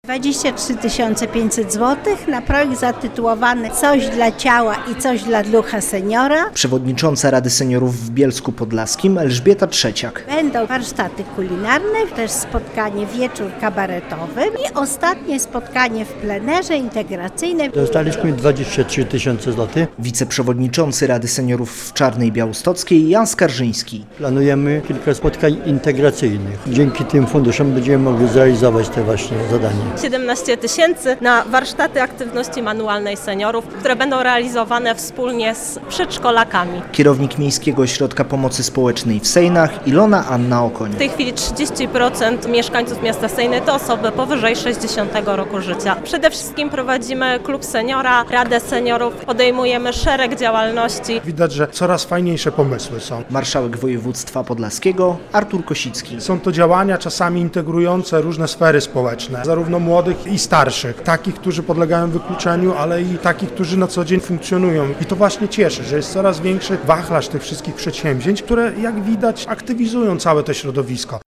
Urząd marszałkowski przyznał 400 tysięcy złotych na działalność podlaskich rad seniorów - relacja